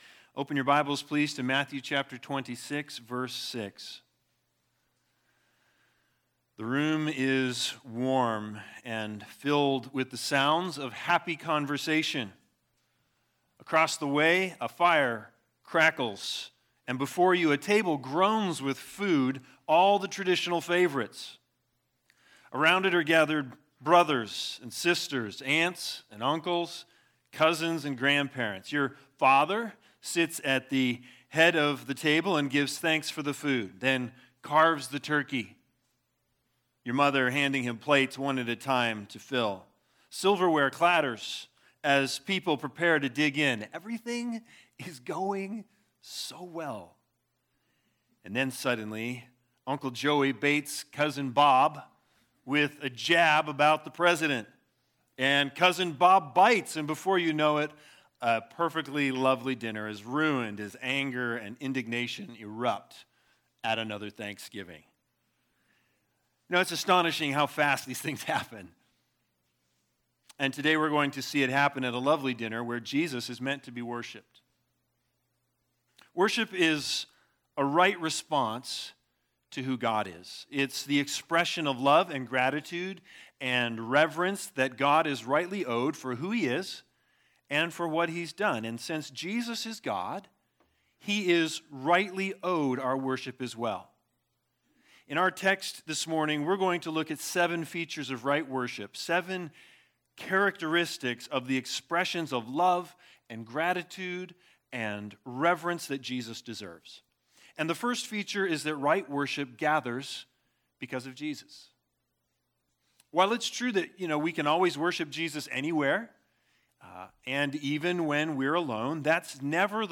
Matthew 26:6-13 Service Type: Sunday Sermons BIG IDEA